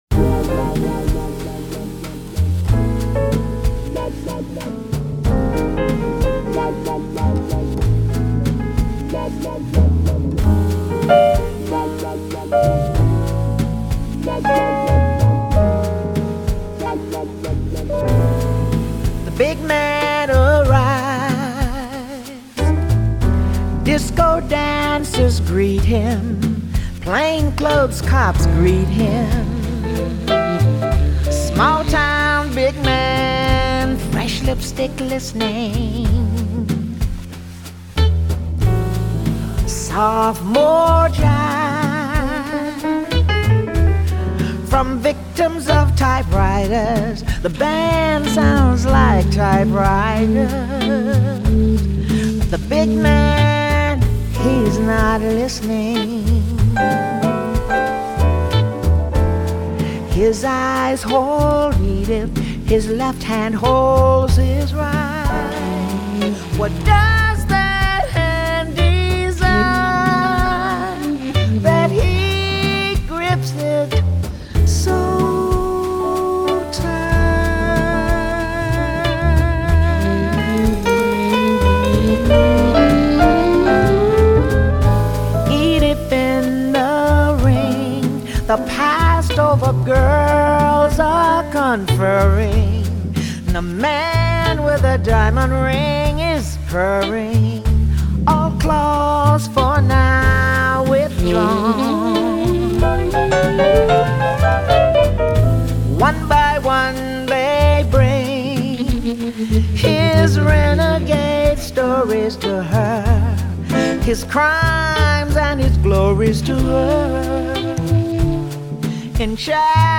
Жанр: jazz